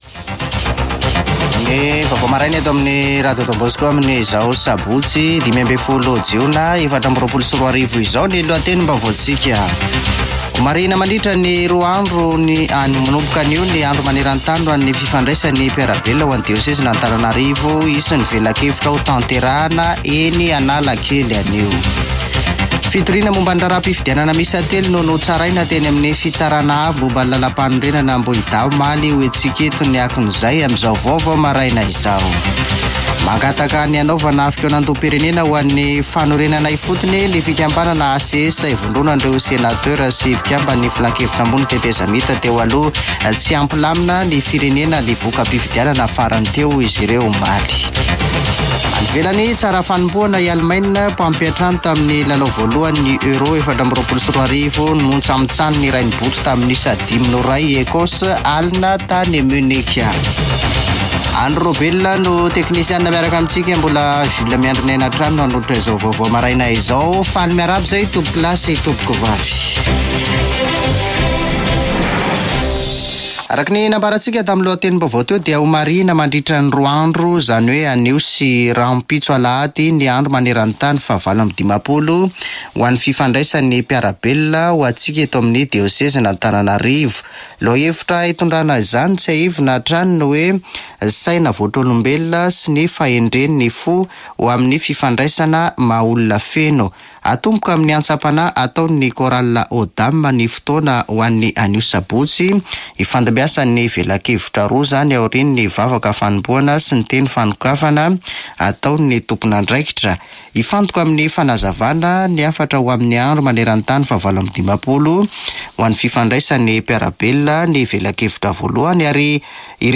[Vaovao maraina] Sabotsy 15 jona 2024